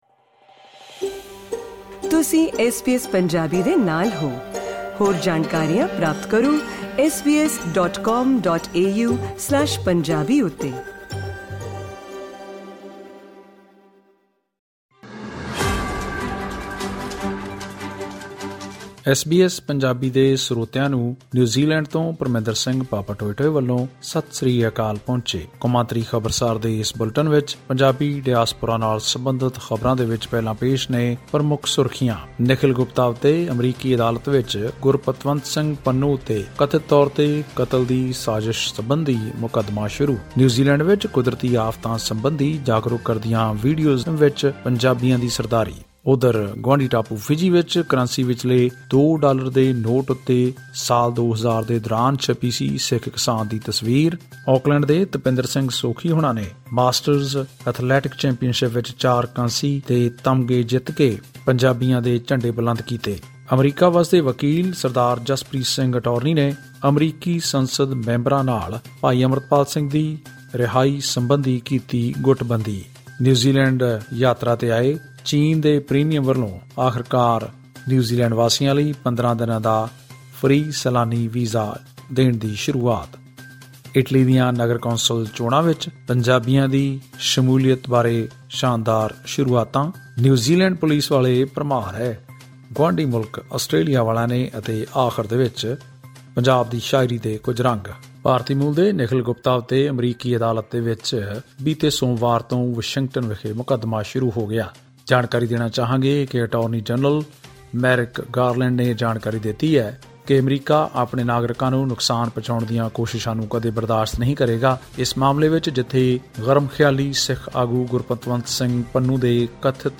ਇਹ ਖਾਸ ਰਿਪੋਰਟ ਸੁਣੋ...